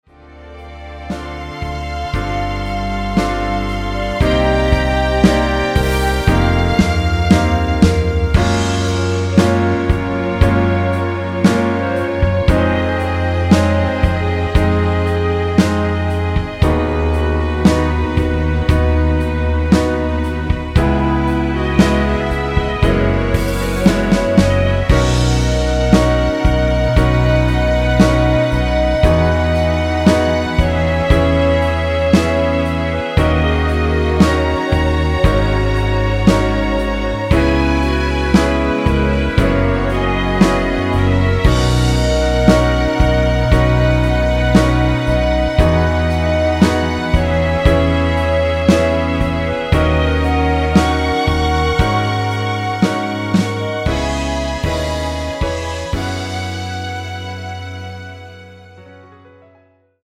원키에서(-2)내린 멜로디 포함된 MR입니다.
Bb
앞부분30초, 뒷부분30초씩 편집해서 올려 드리고 있습니다.